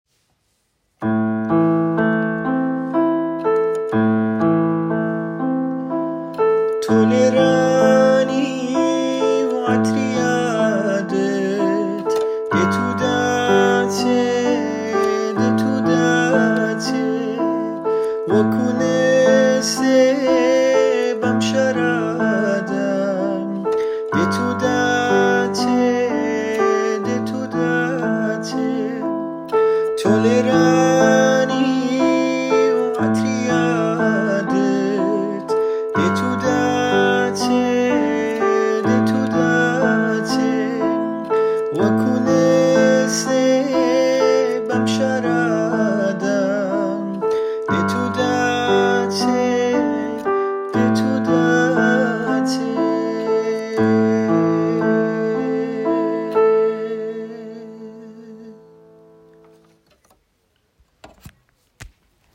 Kurdish music
The vocals will be in Kurdish, and I’m aiming for a sound that blends modern and traditional elements. I already have a rough 1-minute idea recorded, and I’m looking for someone to help me bring it to life — with production, arrangement, or creative input.